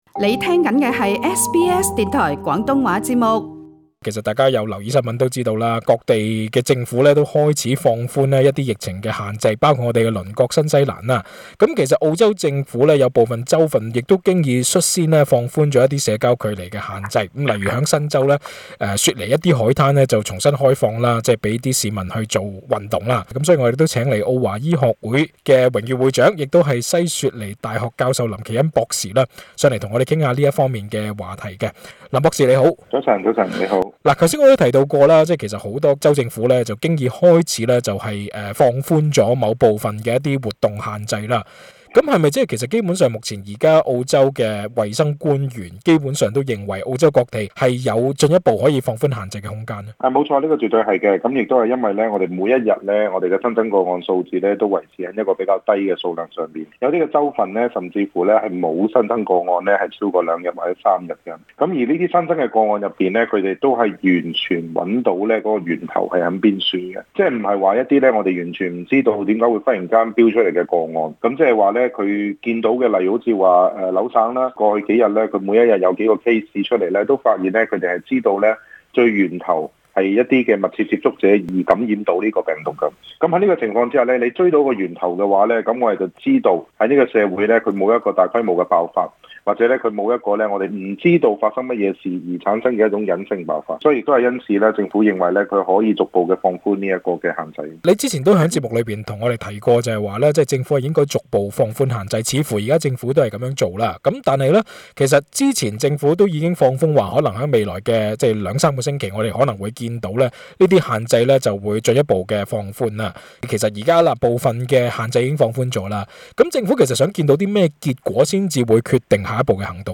到底他提出了甚麼建議，詳情請留意本台的足本訪問。